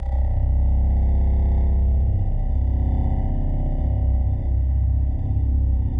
令人毛骨悚然的科幻无人机2
描述：科幻无人机，某种外星人或动力引擎
标签： 怪异 氛围 合成器 令人毛骨悚然 无人驾驶飞机 黑暗 科学-fiction 效果 外星人 FX 空间 动力 科幻 气氛 恐怖
声道立体声